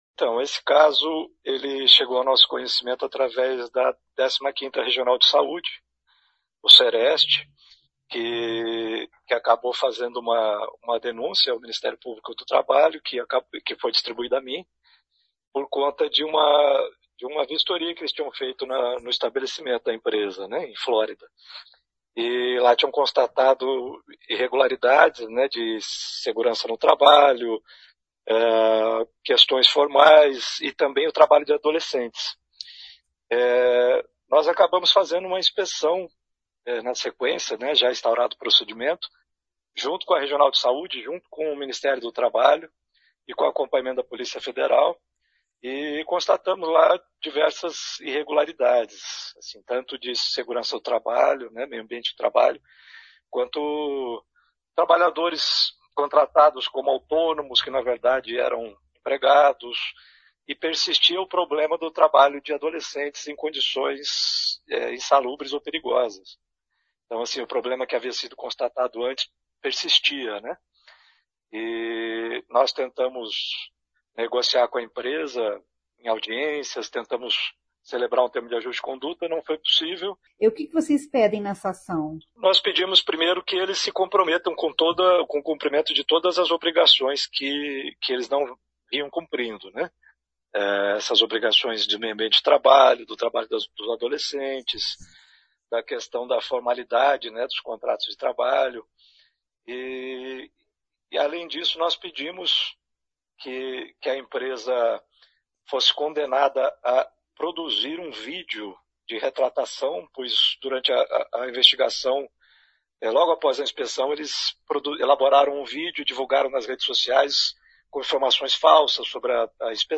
Ouça o que diz o procurador do Trabalho Fábio Alcure: